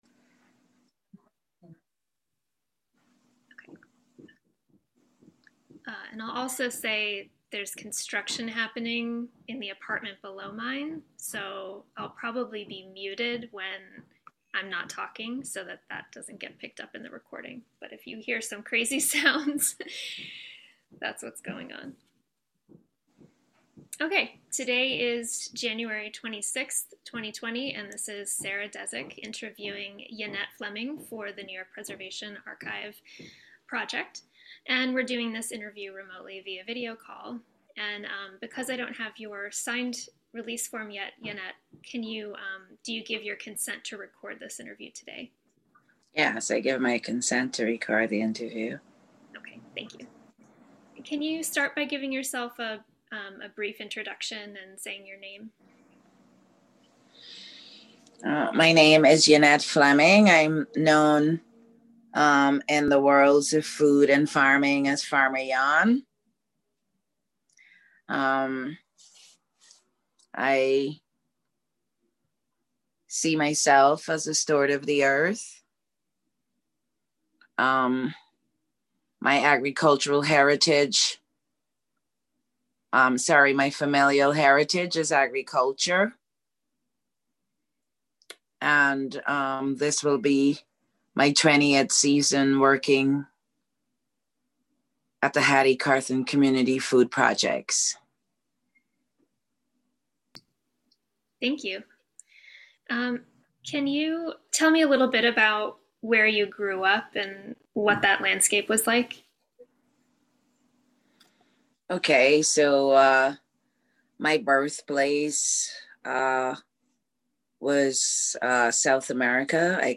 Oral History Intensive